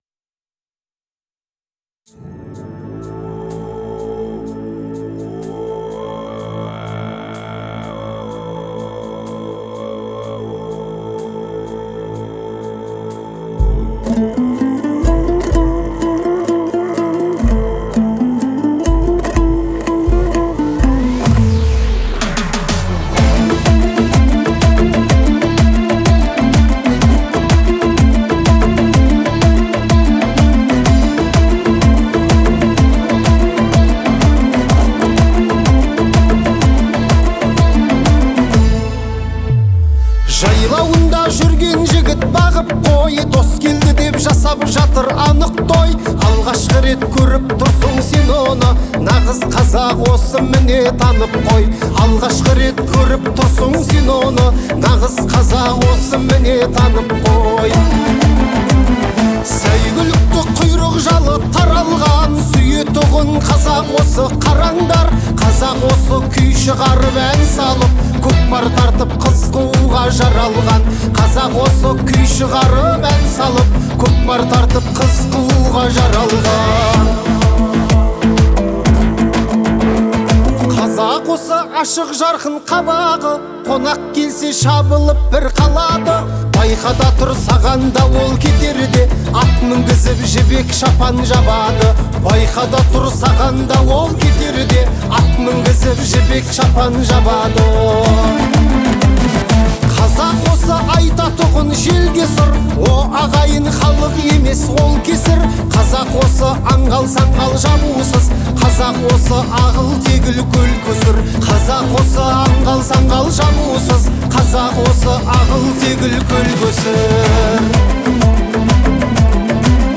это песня в жанре казахского фольклора